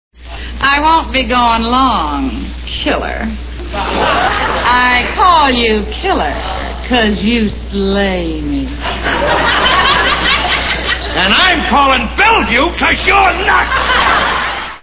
The Honeymooners TV Show Sound Bites